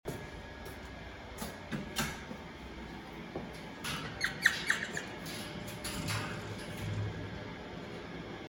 レッサーパンダ鳴き声③ - データセット - オープンデータプラットフォーム | データカタログサイト